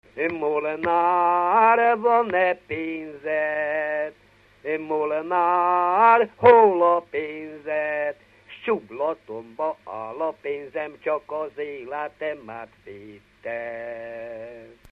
Dunántúl - Somogy vm. - Kisbárapáti
ének
Stílus: 4. Sirató stílusú dallamok